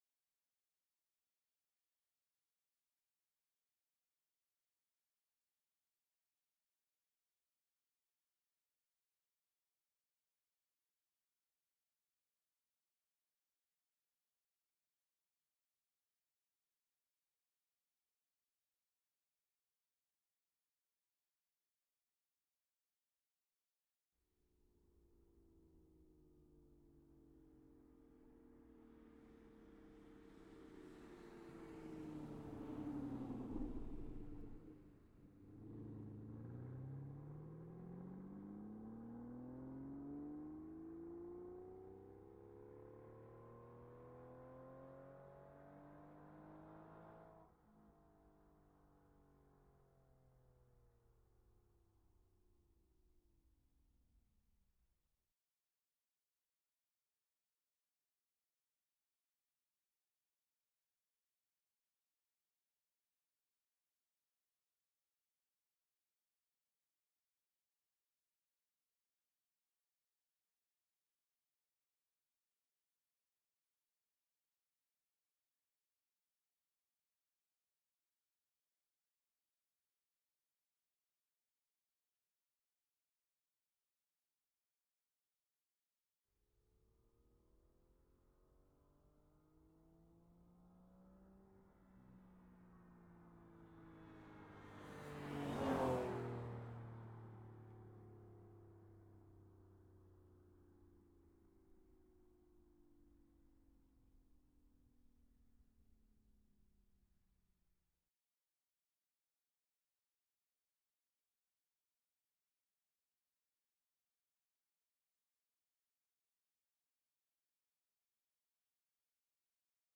Aston_Martin_Rapide_S_t5_Ext_By_Ramps_ORTF_MKH8040.ogg